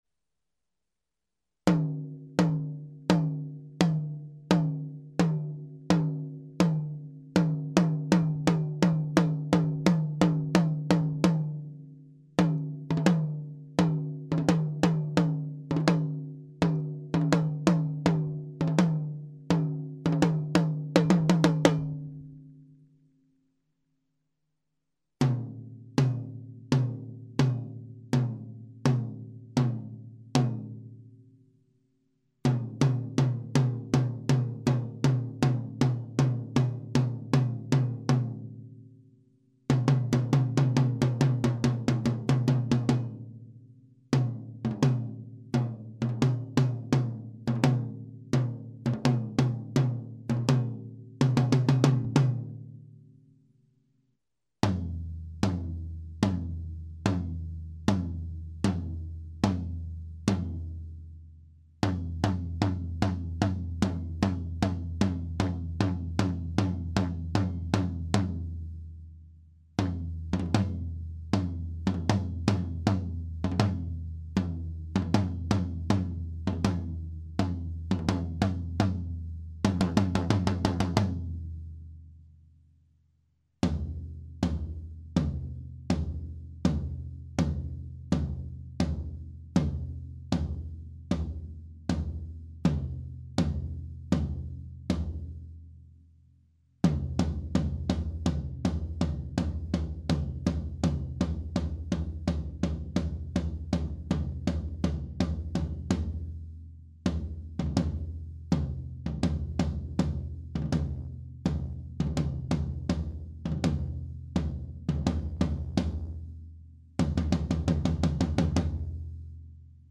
Als Erstes ist immer das Wahan zu hören.
Im Proberaum habe ich mit einem Behringer xr18 und CakeWalk aufgenommenen.
An den größten Toms hing jeweils ein Shure SM 57, an den restlichen Toms Sennheiser e 604.
T1 singt mehr, T2 ist volumniöser/kräftiger, T3 ähnlich begrenzt (leicht abgewürgt im Ton), T4 recht neutral.
Von der ersten Reihe fand ich T1 zu hoch (Geschmackssache), T2 gut, T3 ein bisschen abgewürgt im Ton, T4 sehr schön.